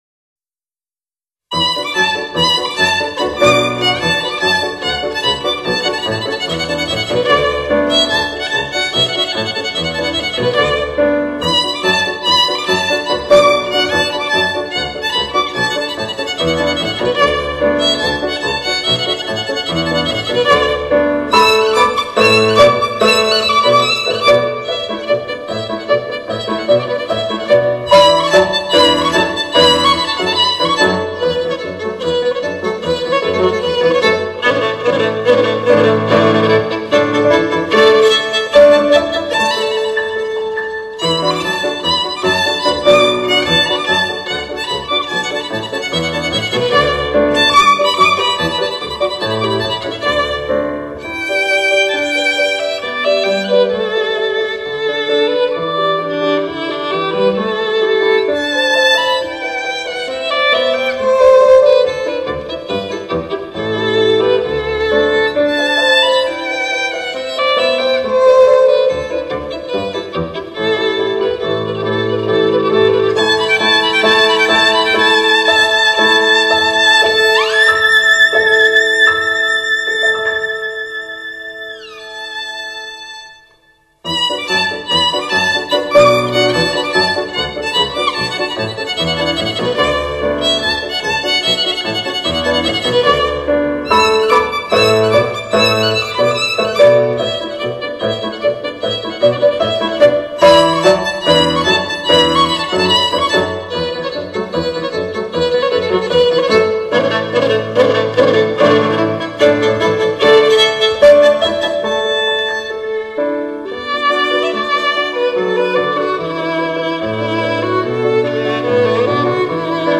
小提琴明亮动听的音色、炫耀的技艺与丰富的表现力，无不使听众倾倒。